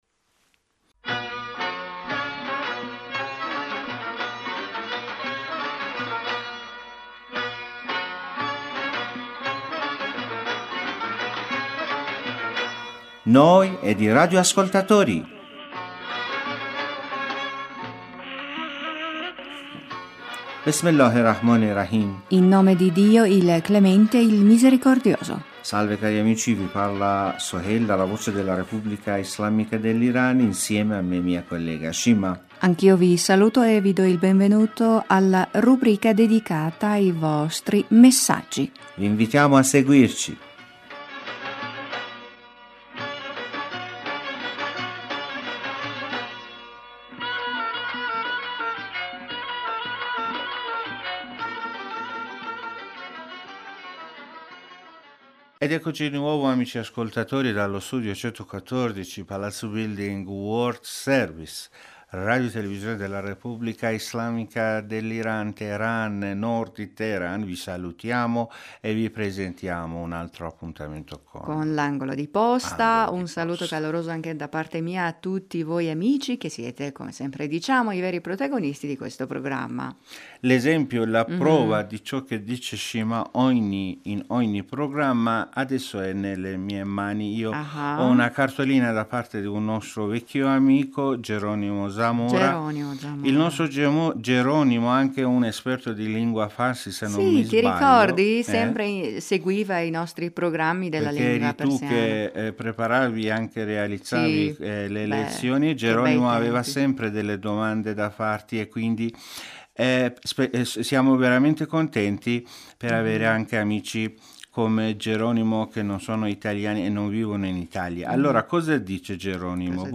Amici in questa puntata potete ascoltare anche una bella canzone persiana!